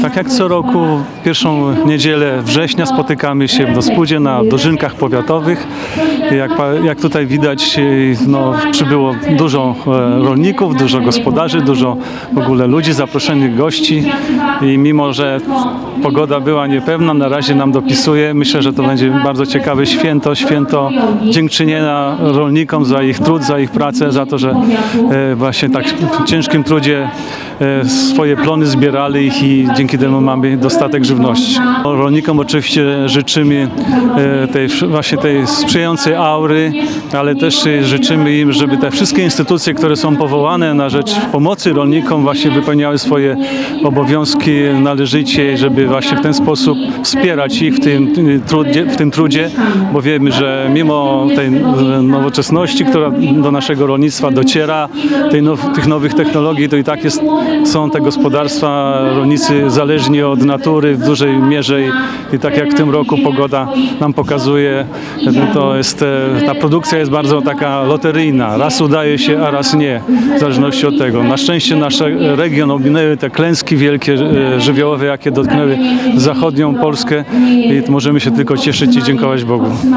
O szczegółach opowiedział Szczepan Ołdakowski, starosta powiatu suwalskiego.